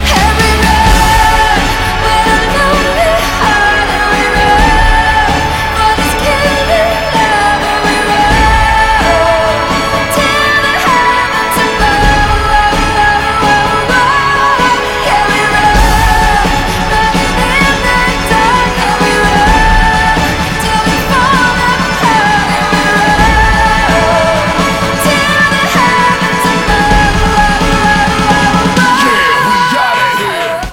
с элементами рэпа